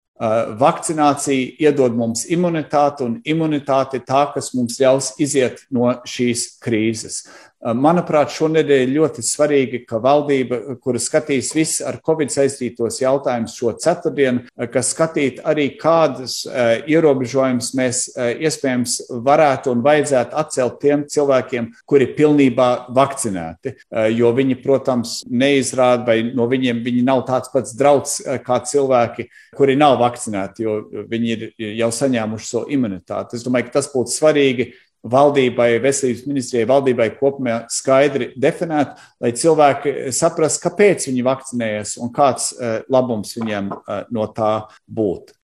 Ministru prezidents pauda pateicību vakcinētājiem un vakcīnu saņēmējiem, piebilstot, ka šis ir svarīgs process, jo vakcīna dod imunitāti, kas ļaus iziet no krīzes. Plašāk Ministru prezidents Krišjānis Kariņš.